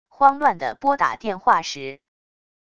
慌乱的拨打电话时wav音频